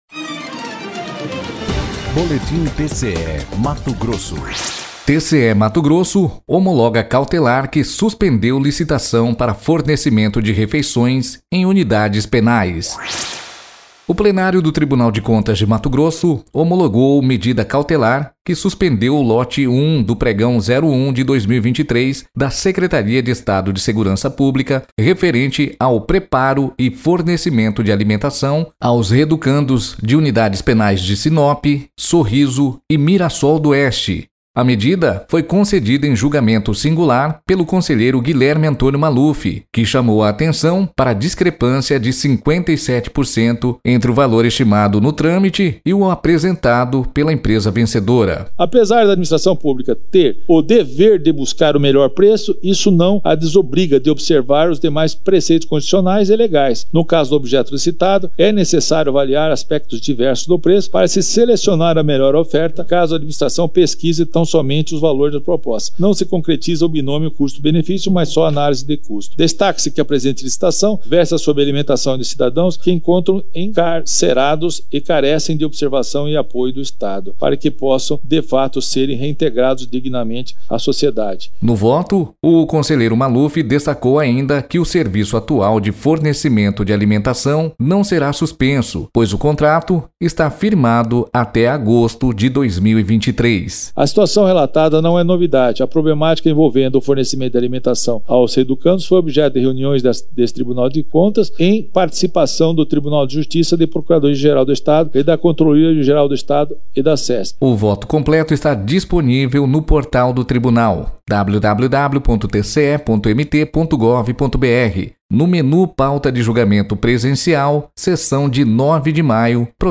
Sonora: Guilherme Antonio Maluf – conselheiro do TCE-MT